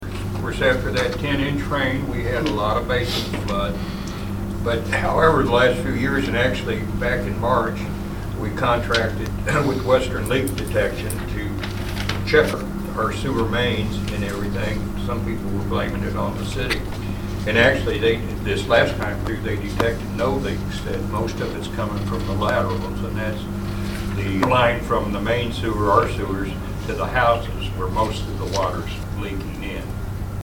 Mayor Dr. Stephen Allegri gave an update.